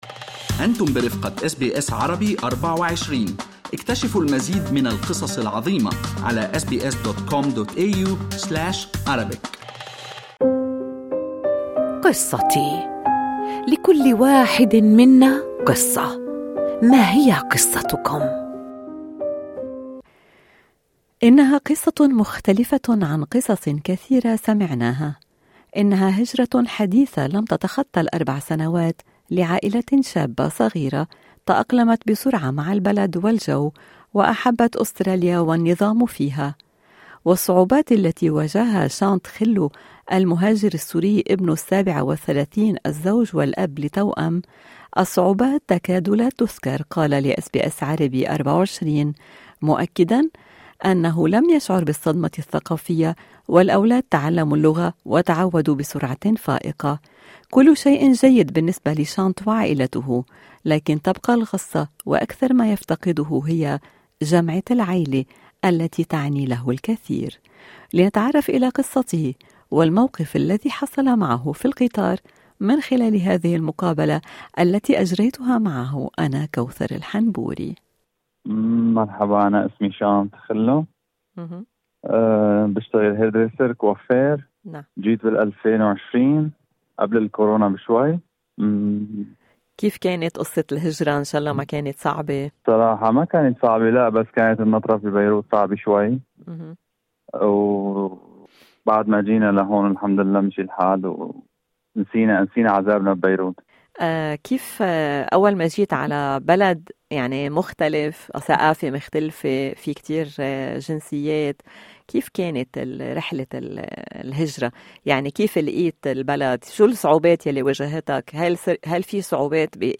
لنتعرف الى قصته والموقف الذي حصل معه في القطار من خلال المقابلة في الملف الصوتي أعلاه: